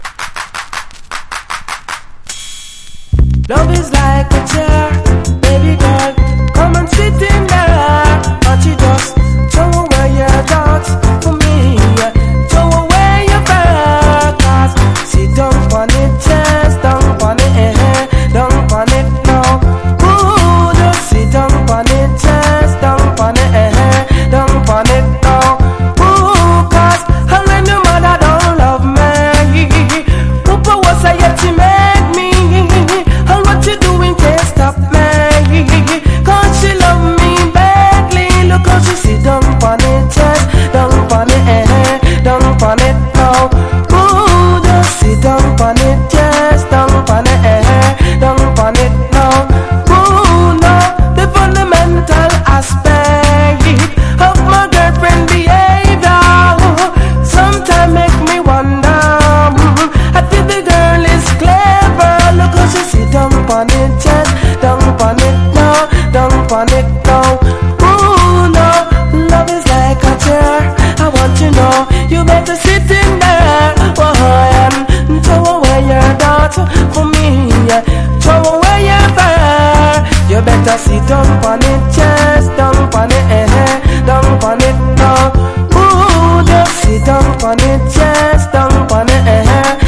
• REGGAE-SKA
# DANCE HALL
(ジャマイカ盤特有のチリノイズ入ります)